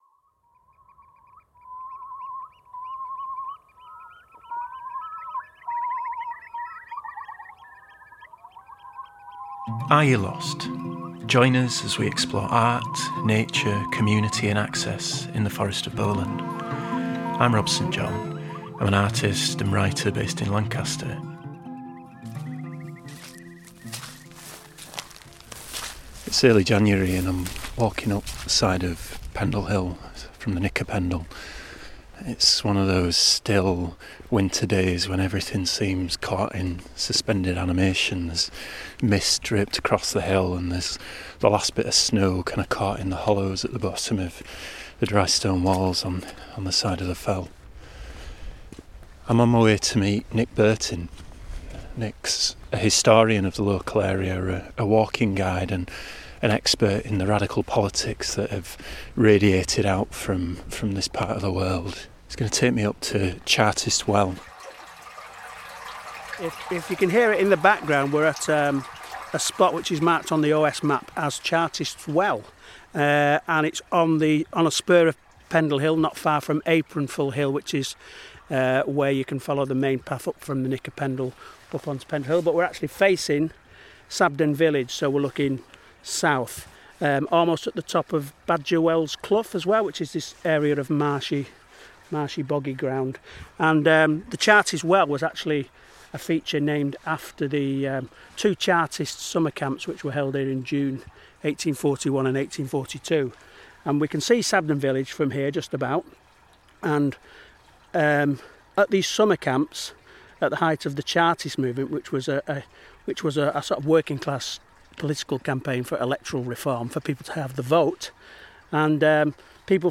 at the Fairy Holes Cave above Whitewell in the Forest of Bowland. Their chats cover Pendle Hill’s radical histories, rights of access movements, Quaker revelations, the founding of the Pennine Way, folklore, cairns, ruins, caves, death…